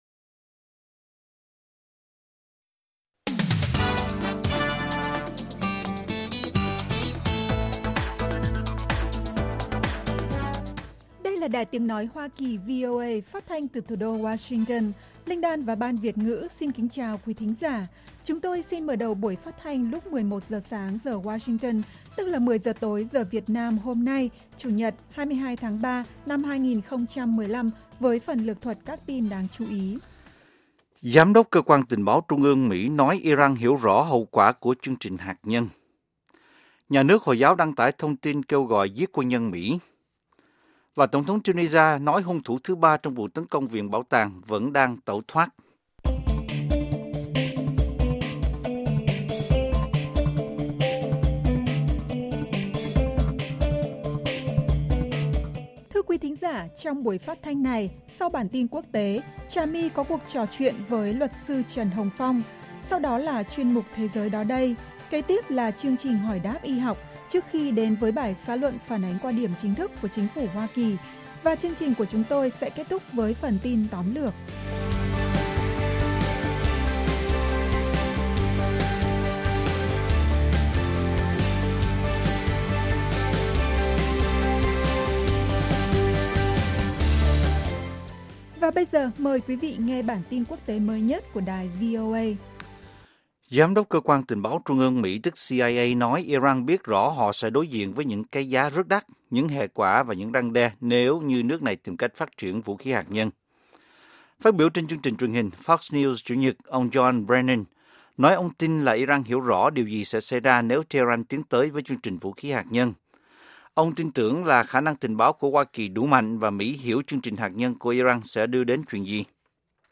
Các bài phỏng vấn, tường trình của các phóng viên VOA về các vấn đề liên quan đến Việt Nam và quốc tế, và các bài học tiếng Anh.